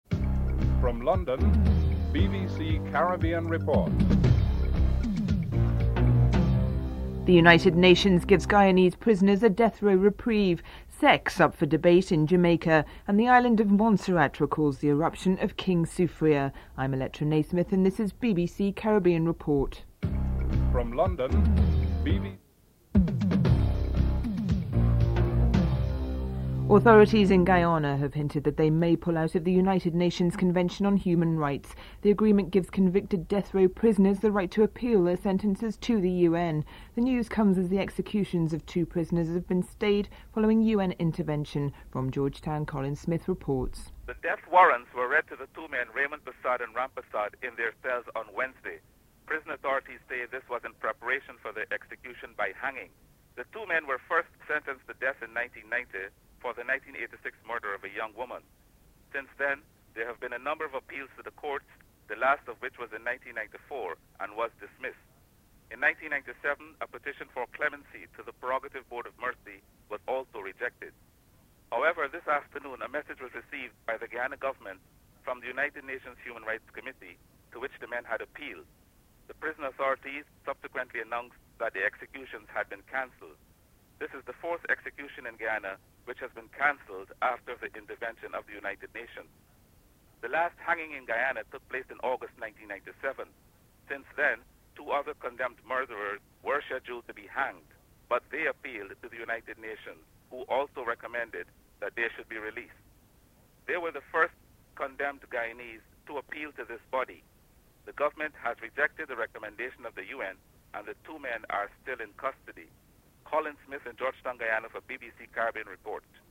1. Headlines (00:00-00:26)
OECS Ambassador to Brussels Edwin Laurent is interviewed (03:51-06:28)